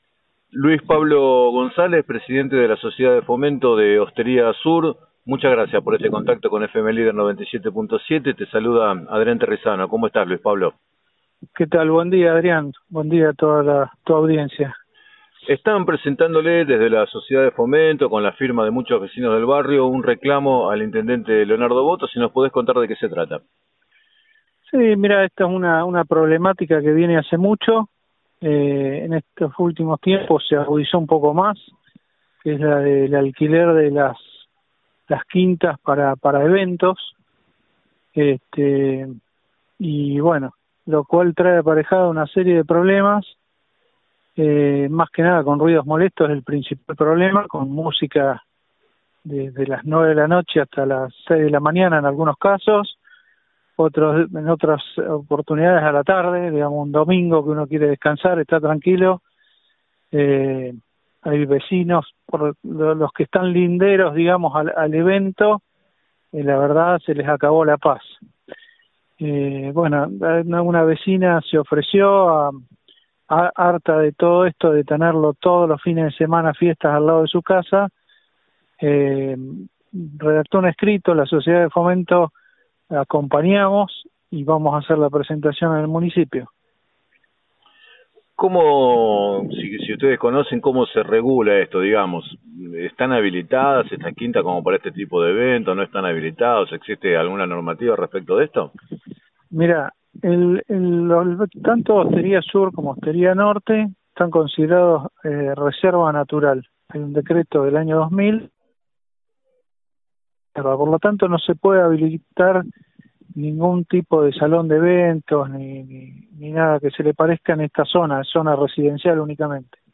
En declaraciones al programa 7 a 9 de FM Líder 97.7